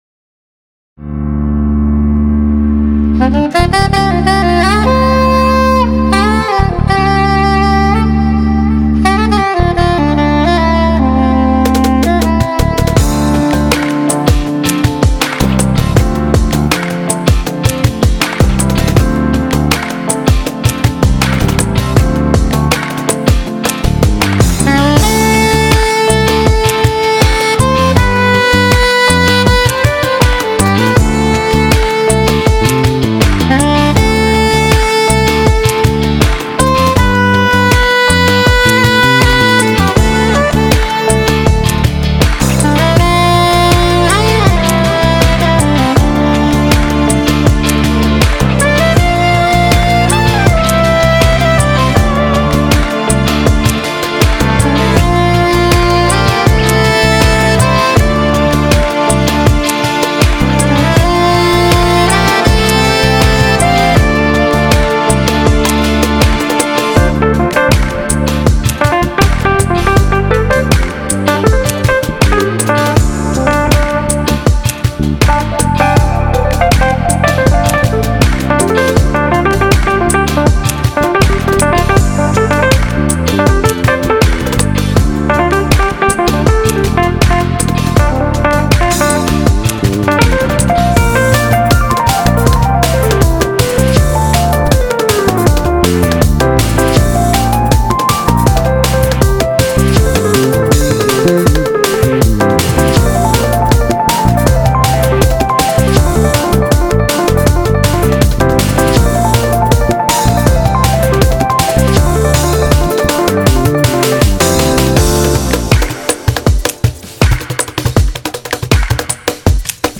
بی کلام
نوازنده مهمان
الکترو جز